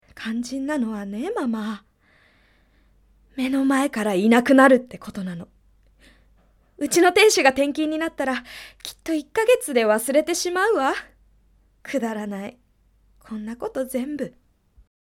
静かに悲しむ女性
ボイスサンプル